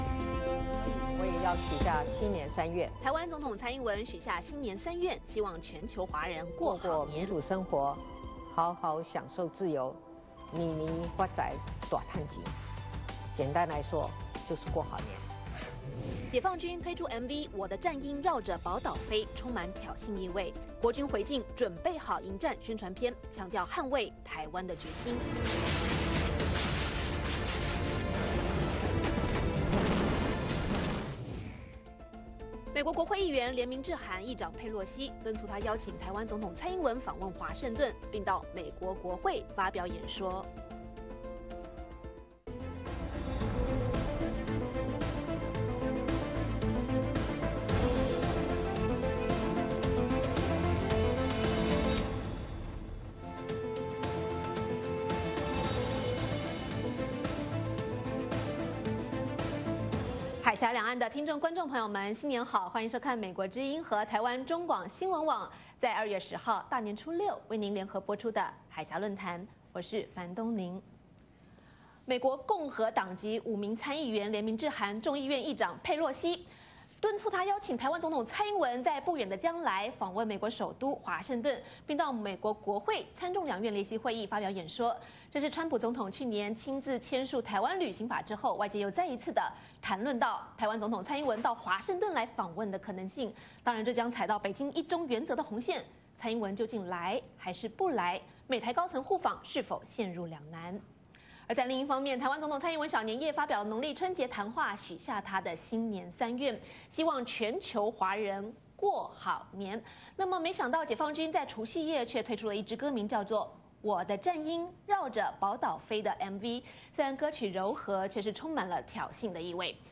美国国会议员联名致函议长佩洛西，敦促她邀请台湾总统蔡英文访问华盛顿，并到美国国会发表演说，这是川普总统签署台湾旅行法后，外界再一次谈论到蔡英文访美的可能性，蔡英文来与不来是否陷入两难？另一方面 ，蔡英文许下“新年三愿”，希望全球华人“过好年”，解放军推出MV“我的战鹰绕着宝岛飞”，充满挑衅意味，国军回敬“准备好迎战”宣传片，强调捍卫台湾的决心，今晚《海峡论谈》请两位来宾谈谈两岸领导人与军方过年期间的相互较劲以及蔡英文究竟是不是有可能正式访美?